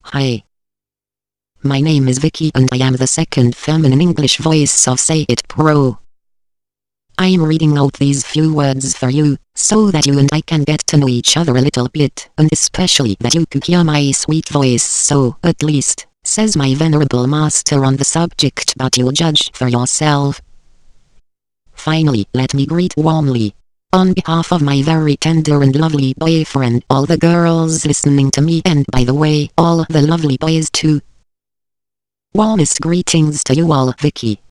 Texte de démonstration lu par Vicky, deuxième voix féminine anglaise de LogiSys SayItPro (Version 1.70)